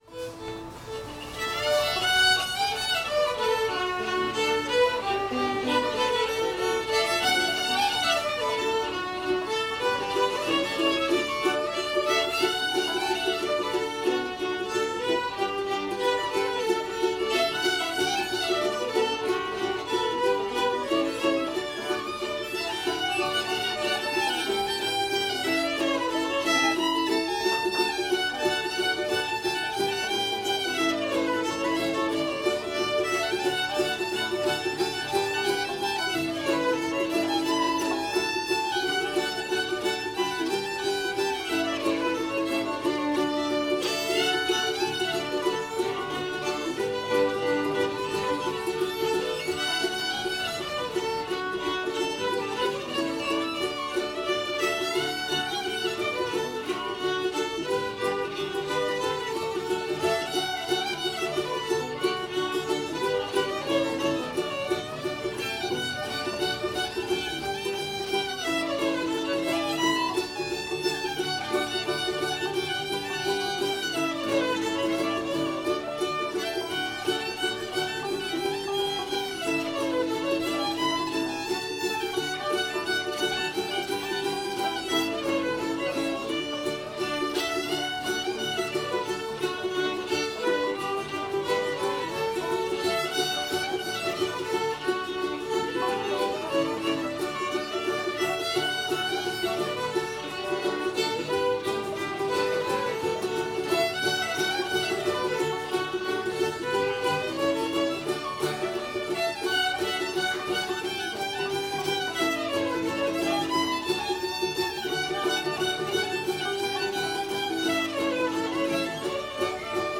st anne's reel [D]